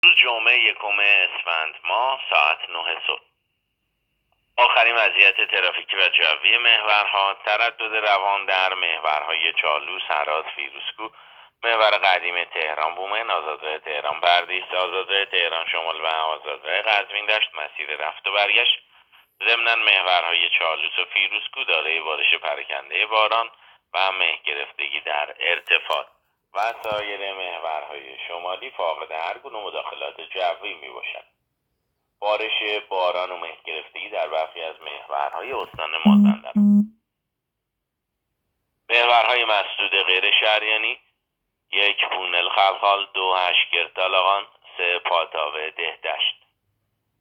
گزارش رادیو اینترنتی از آخرین وضعیت ترافیکی جاده‌ها ساعت ۹ اول اسفند؛